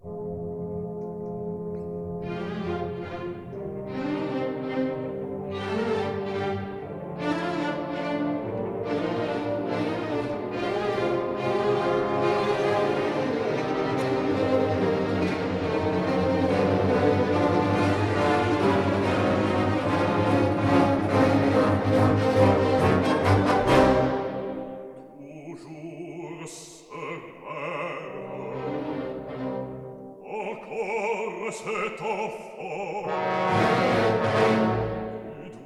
Scene 2: Herods aria.
Stereo recording made in London